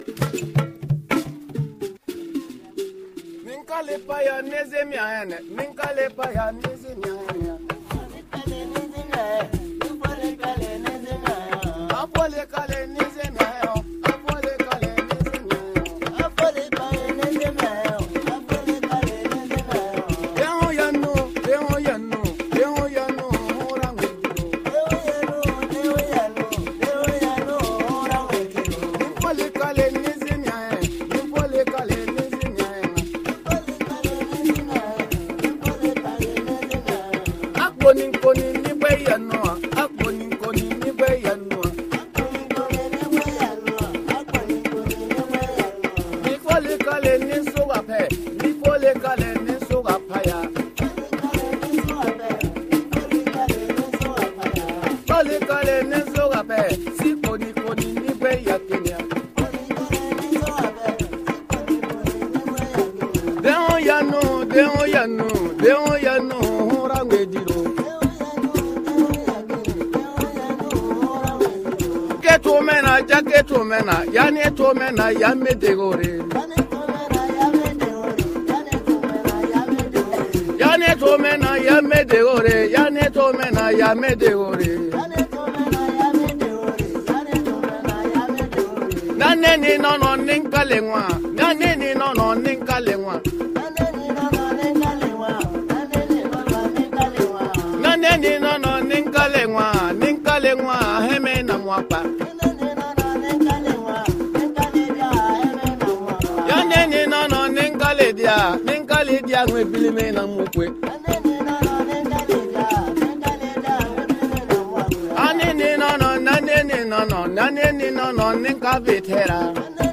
Laissez-vous bercer par la musique traditionnelle pwo.
avec sa kora et sa voix langoureuse